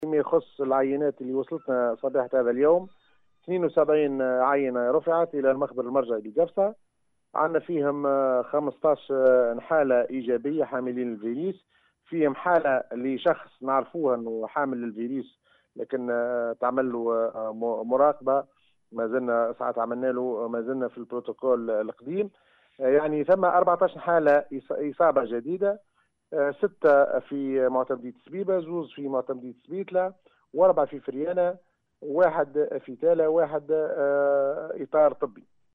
المدير الجهوي للصحة عبد الغني الشعباني: